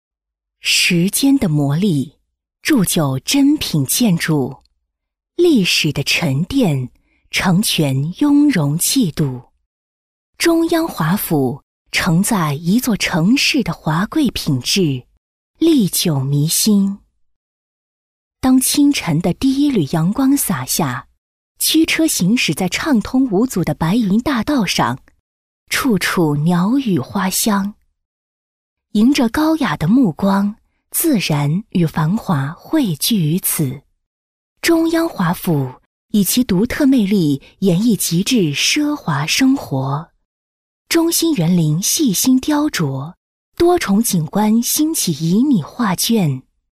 C女71号
【广告】大气地产
【广告】大气地产.mp3